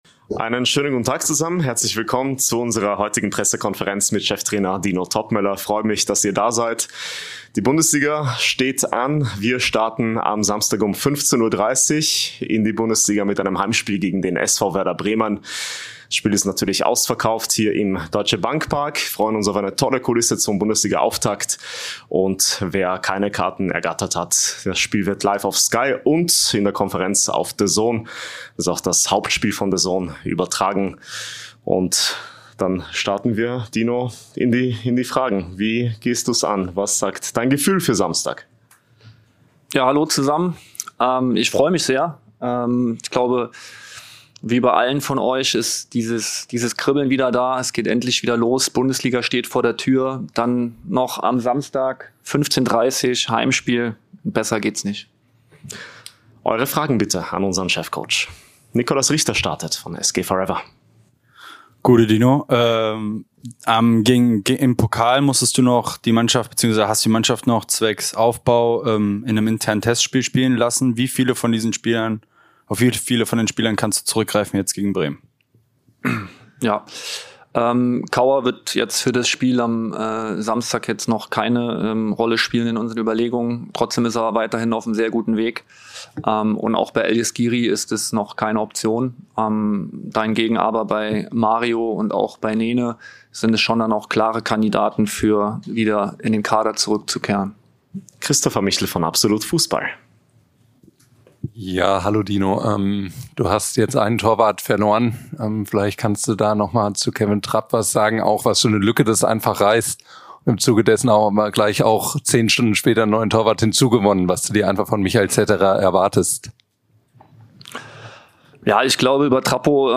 Der Bundesligaauftakt steht an und unser Cheftrainer Dino Toppmöller beantwortet die Fragen der Medienvertreter.